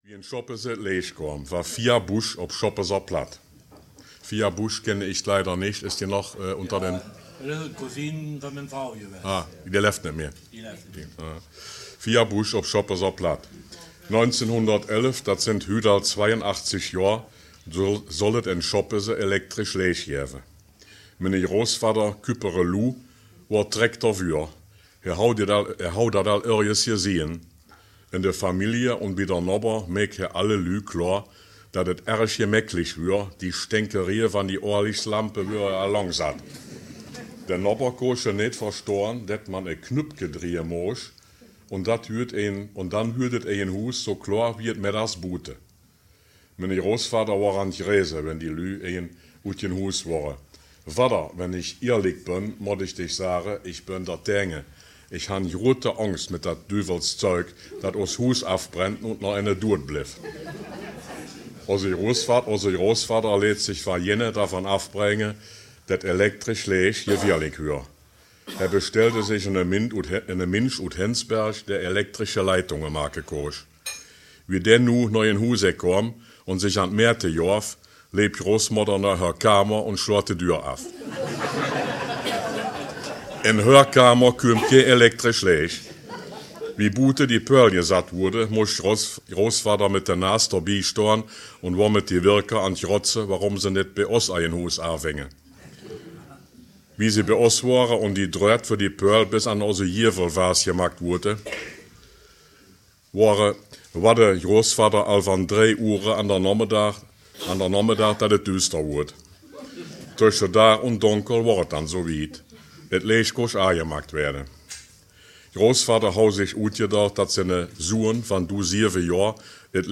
Text Mundart